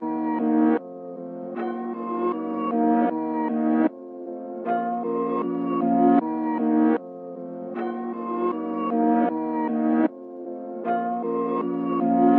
Tag: 155 bpm Trap Loops Piano Loops 2.08 MB wav Key : E FL Studio